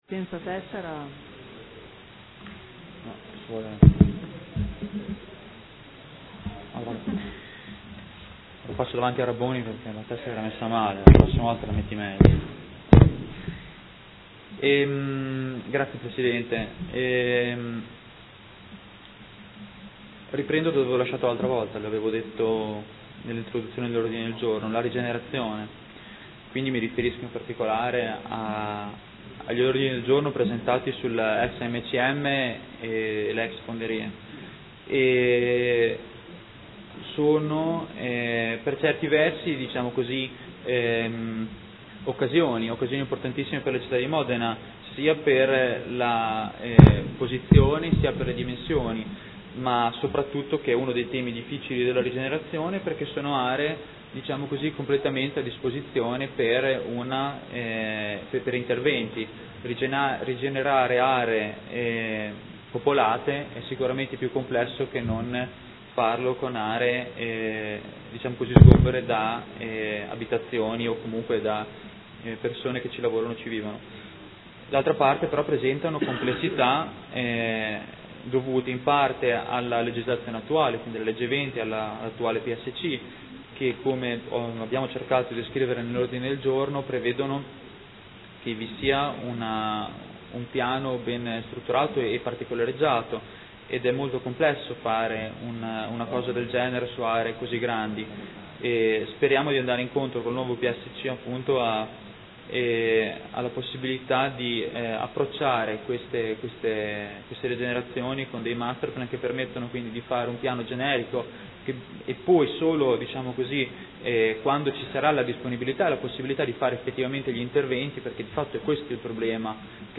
Diego Lenzini — Sito Audio Consiglio Comunale
Seduta del 29/10/2015 Dibattito sugli ordini del giorno.